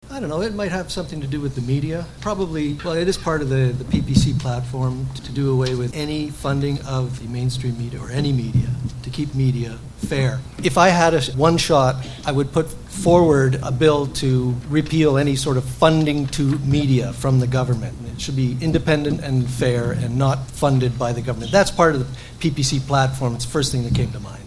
All-candidates debate, hosted by the Simcoe and District Chamber of Commerce